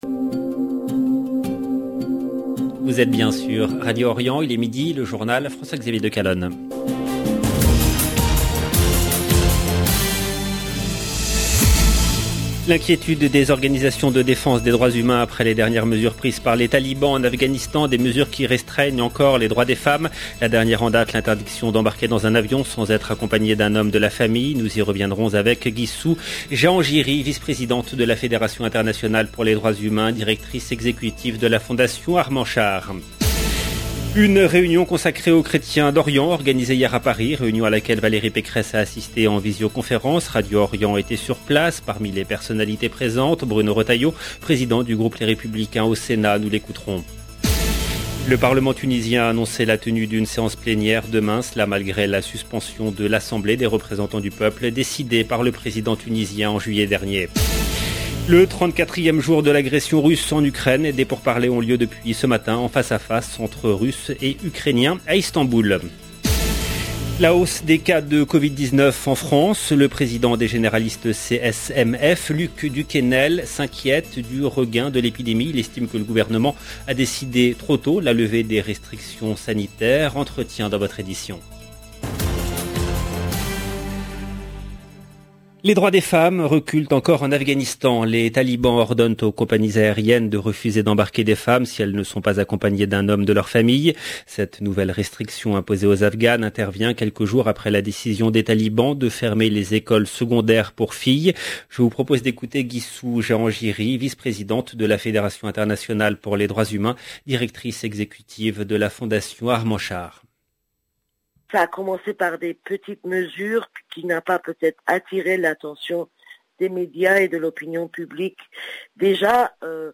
LE JOURNAL DE MIDI EN LANGUE FRANCAISE DU 29/03/22 LB JOURNAL EN LANGUE FRANÇAISE